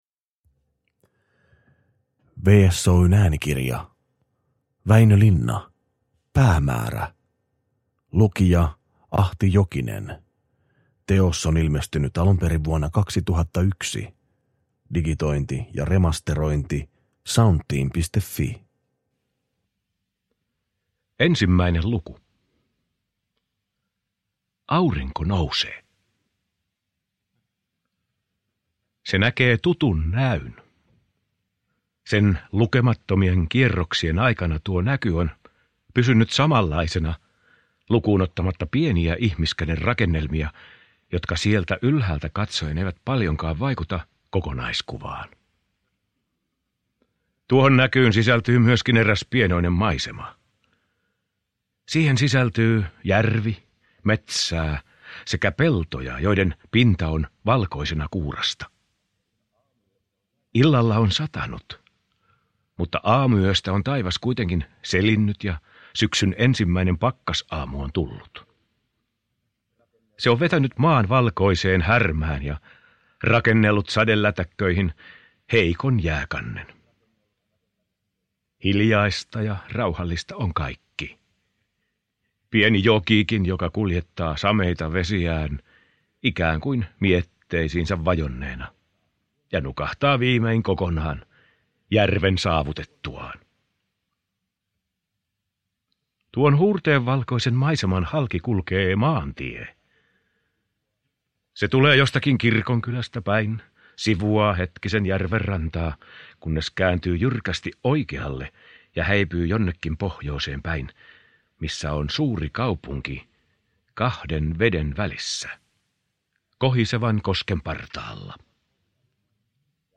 Päämäärä – Ljudbok